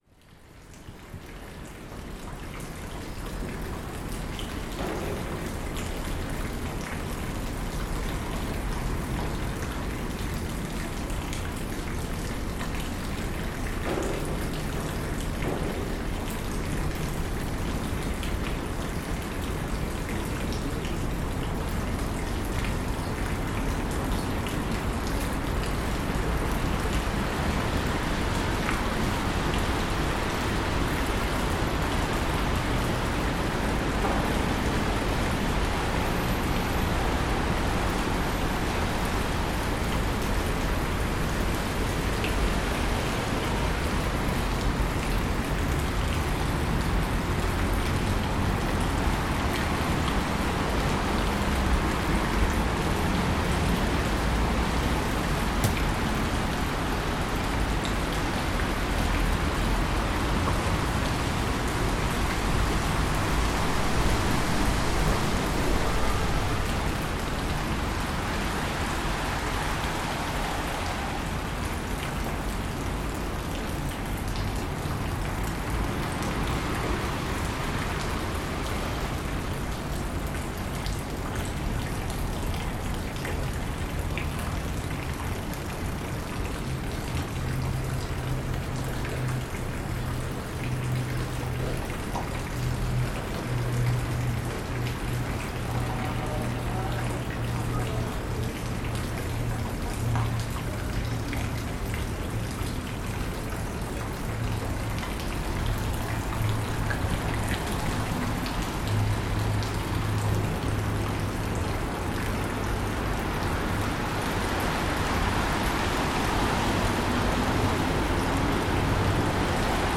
Rain, flowing water, traffic and pigeons on a rainy day beneath the Stadthausbruecke, Hamburg, October 2014.